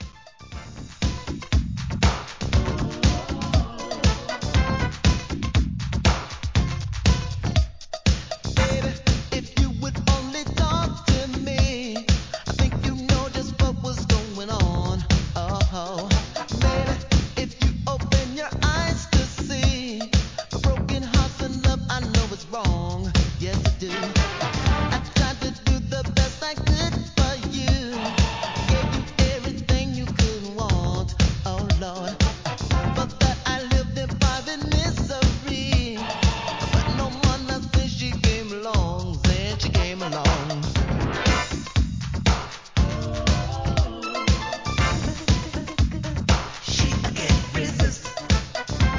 ¥ 770 税込 関連カテゴリ SOUL/FUNK/etc...